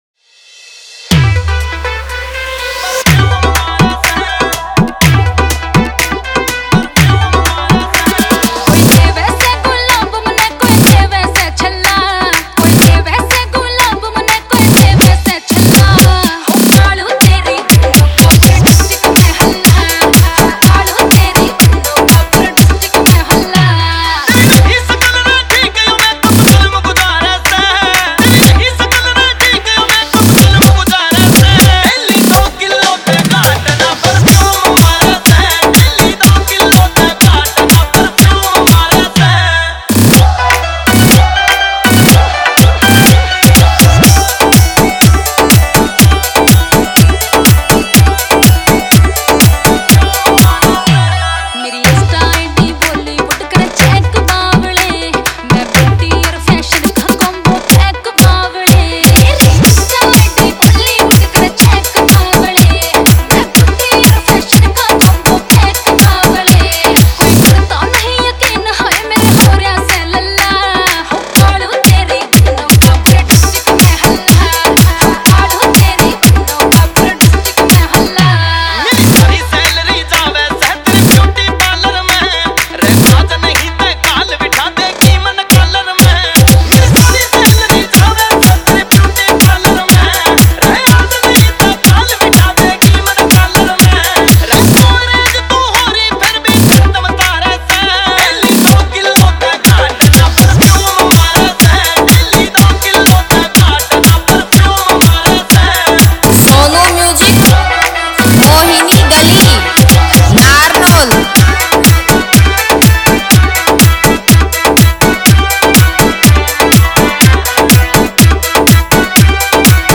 Album Haryanvi Remix Song (2024)